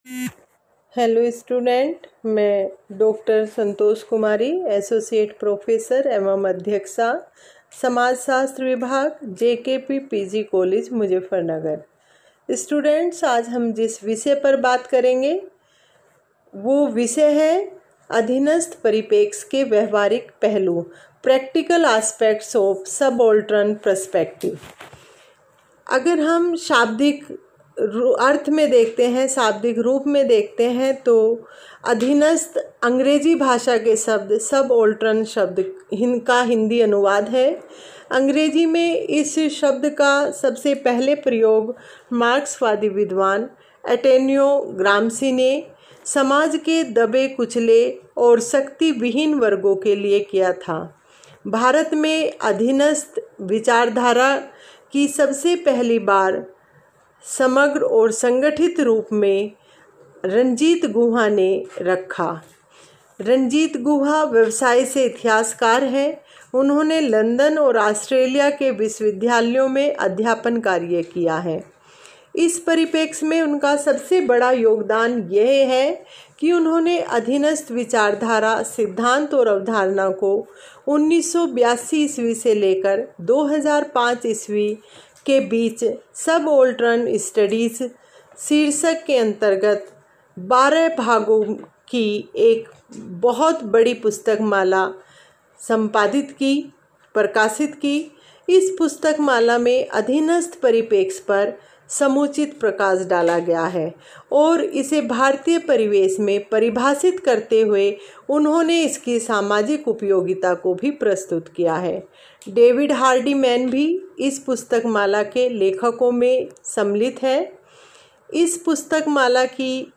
Audio lecture On subaltern perspective in lndian Sociology
Audio-lecture-On-subaltern-perspective-in-lndian-Sociology.m4a